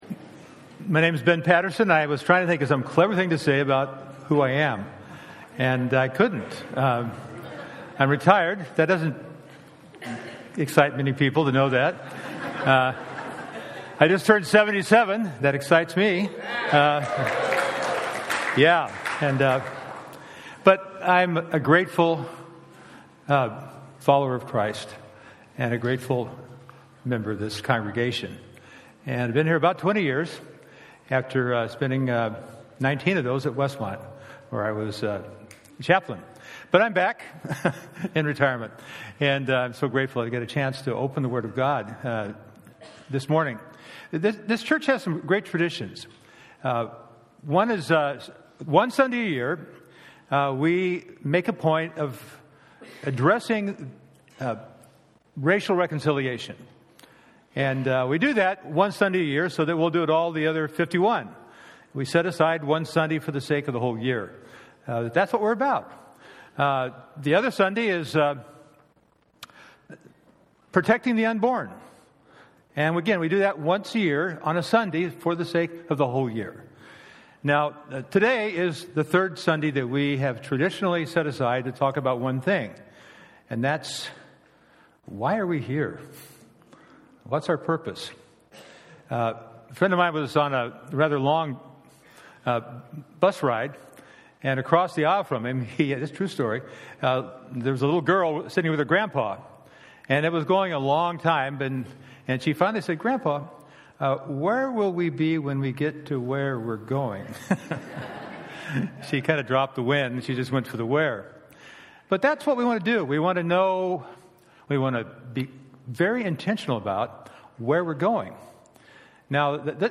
Bible Text: Psalm 19 | Speaker: Guest Preacher | Series: New Year…